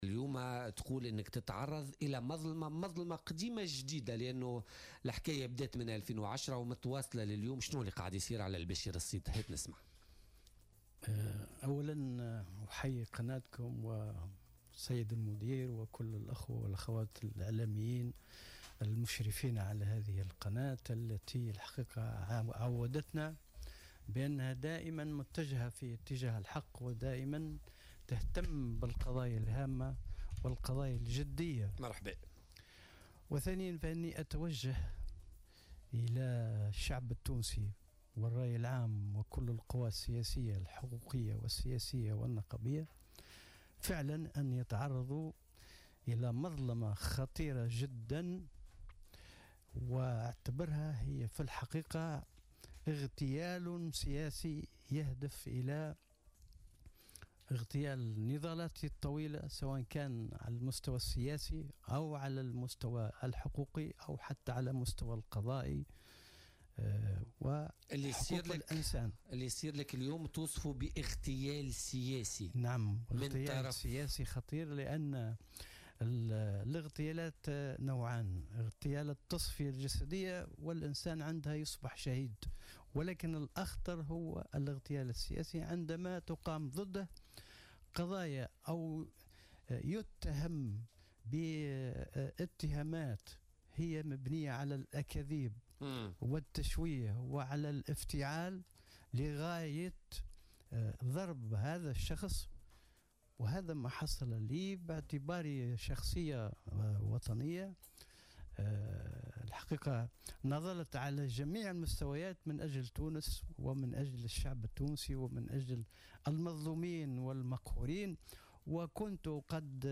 أكد عميد المحامين السابق بشير الصيد ضيف بولتيكا اليوم الأربعاء 4 أفريل 2018 أنه...